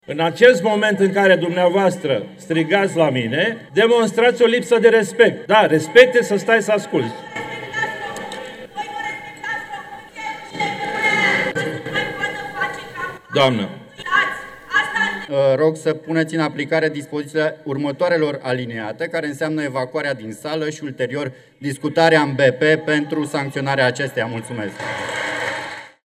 Totuși, Predoiu a fost întrerupt de numeroase ori de către lidera POT, Anamaria Gavrilă, care a provocat un întreg scandal, deși niciun deputat POT nu a semnat moțiunea simplă.